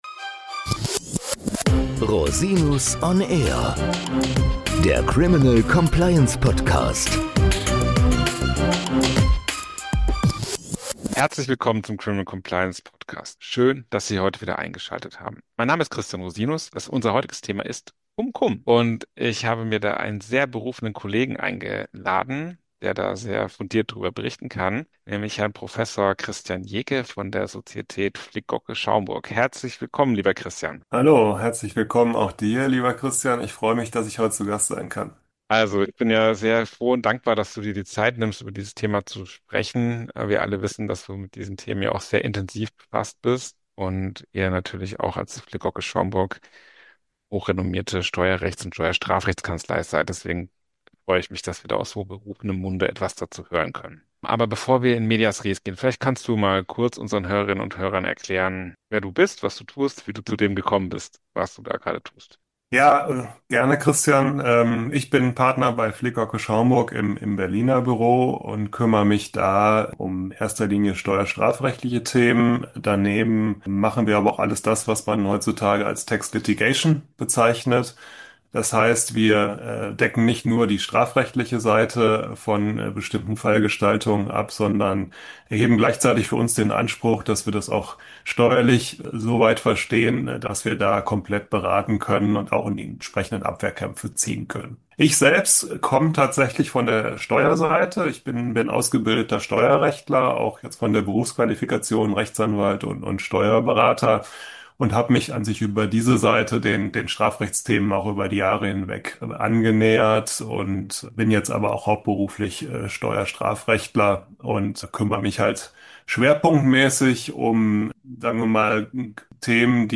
Cum-Cum Geschäfte - Interview